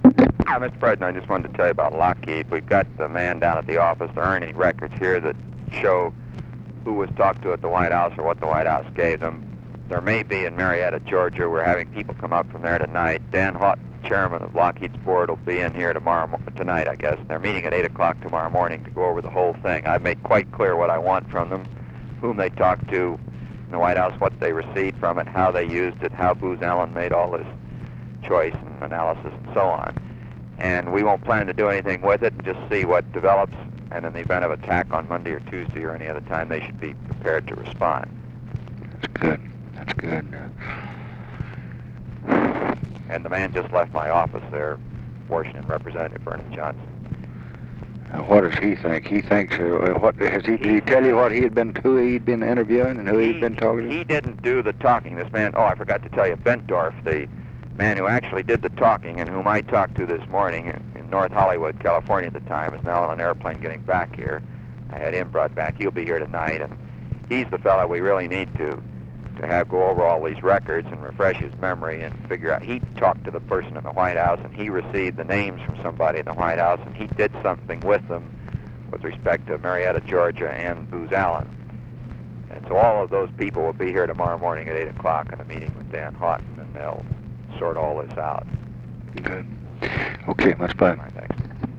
Conversation with ROBERT MCNAMARA, February 18, 1967
Secret White House Tapes